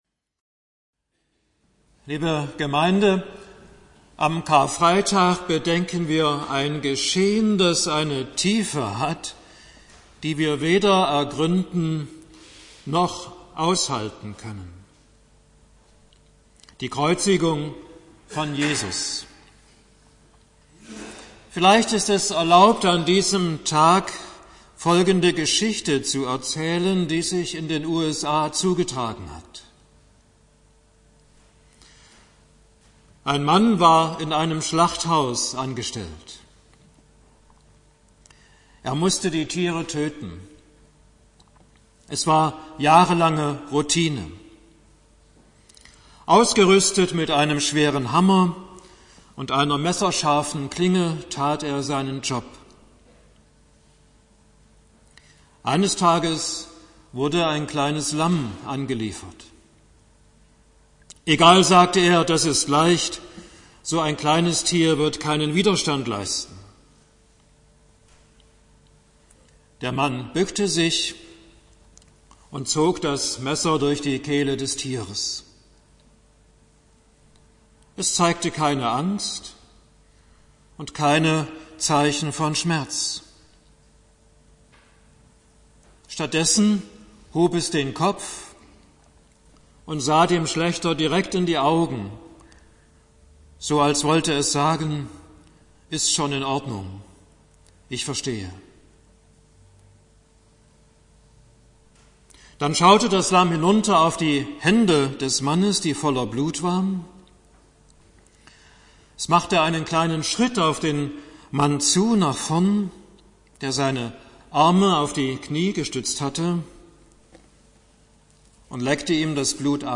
Predigt zu Karfreitag